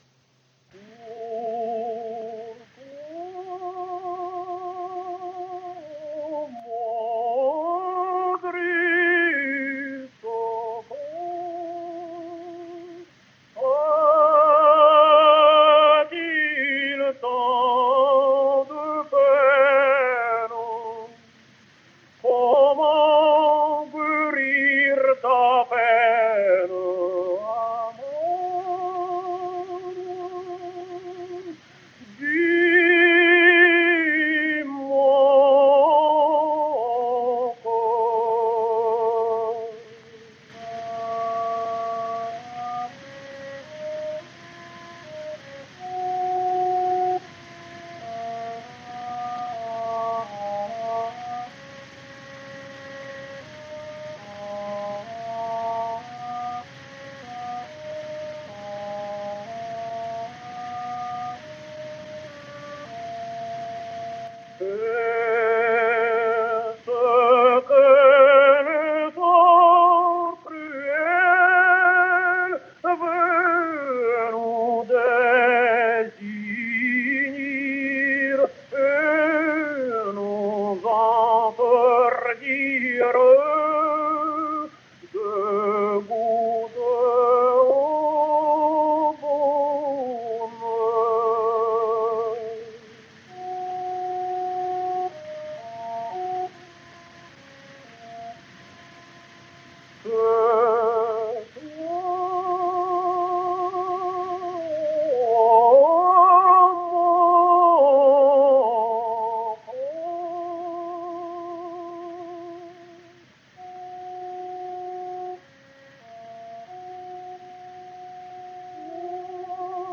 Belarusian Tenor
A minimal but persistent trommolo, scarcely noticeable on short notes, on extended notes it consistently marked his singing.